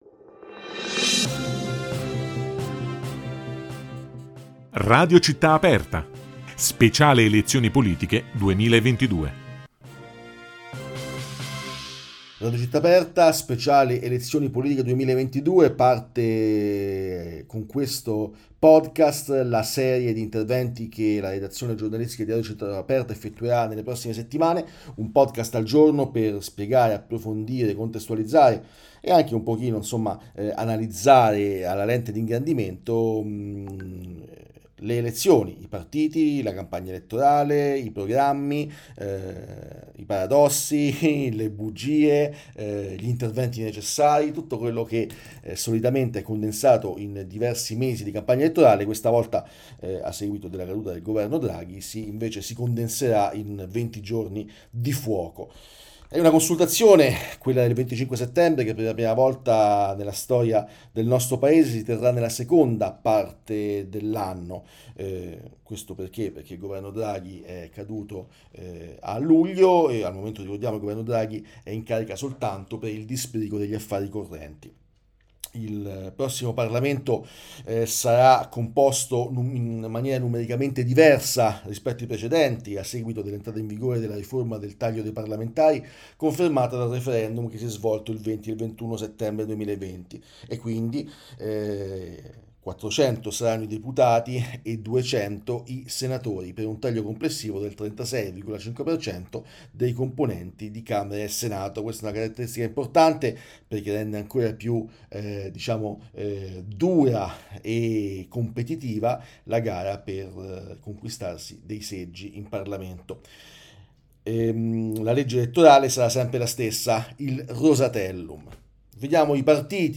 Elezioni politiche 2022: una serie di podcast in cui i giornalisti RCA commentano ogni giorno i singoli programmi dei partiti e schieramenti che si sfideranno il prossimo 25 settembre